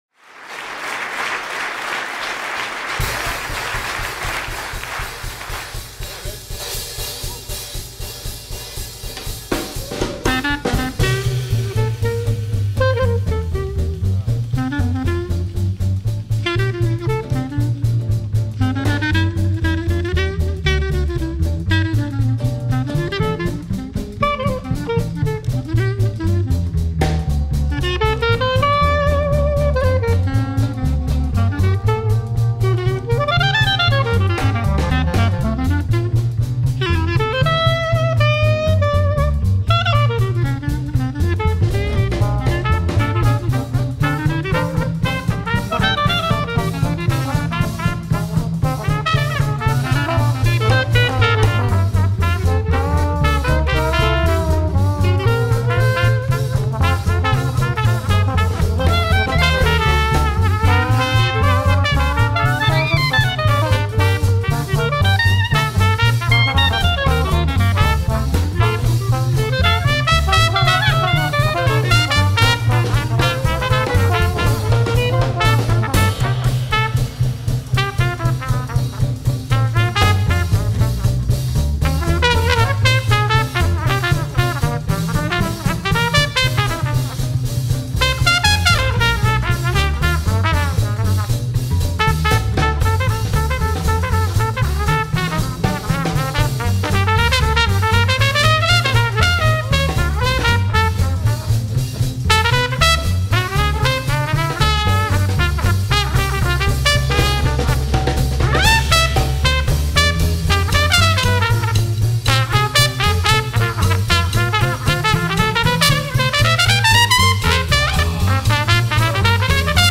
il reste fidèle aux racines du swing
trompette
trombone, cornet, vocal
saxophone, clarinette
piano
contrebasse
batterie